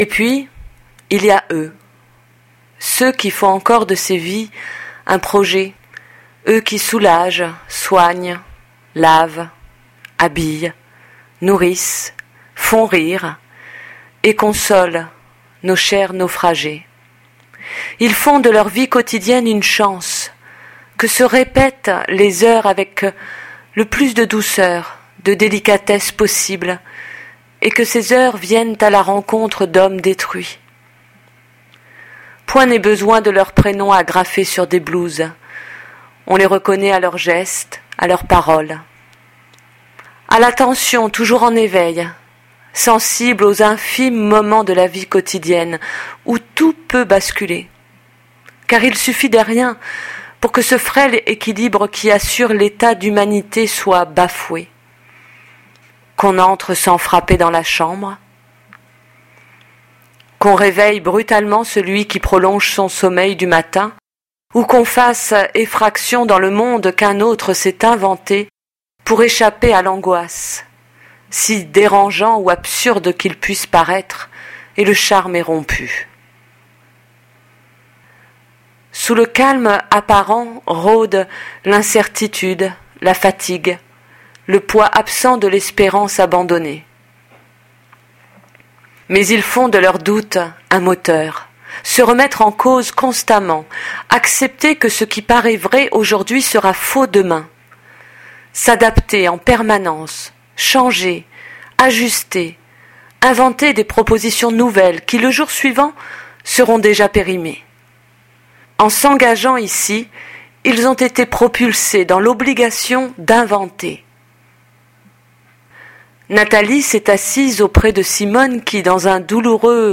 Lecture Paroles